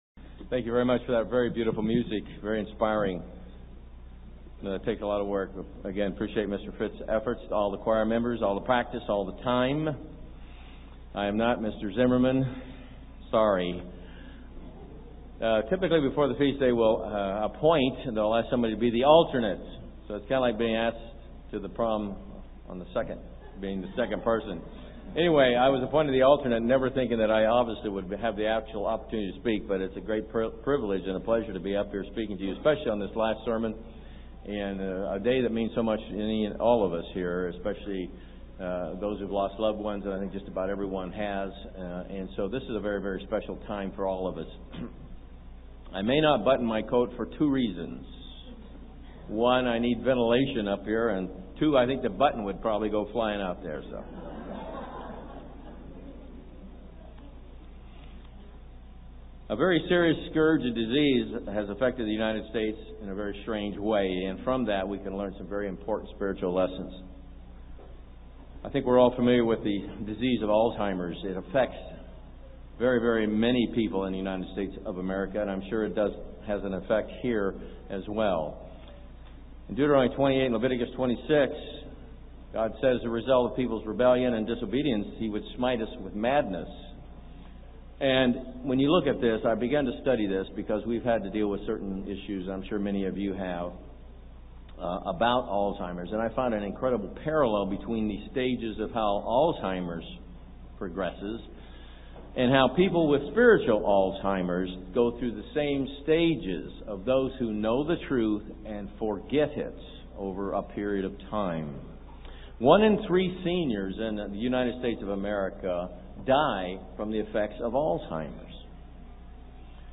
This sermon was given at the Jamaica 2013 Feast site.